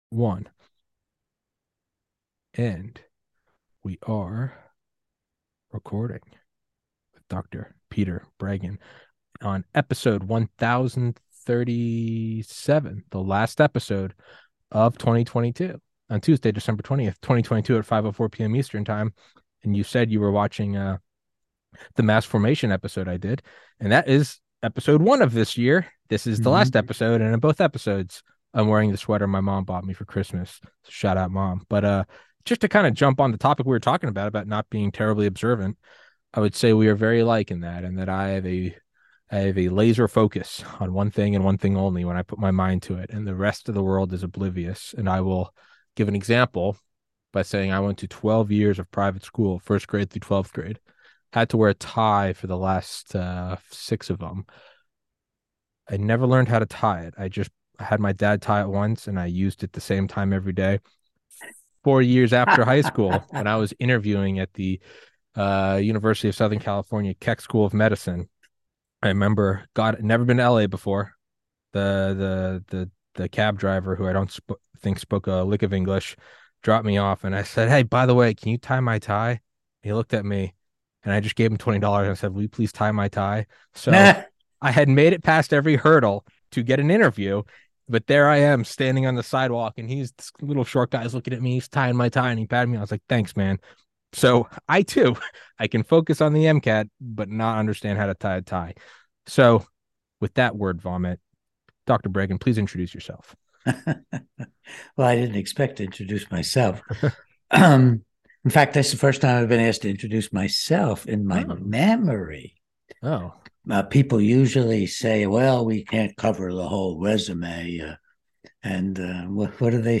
Peter Breggin MD in a touching year-end interview: Act with Love~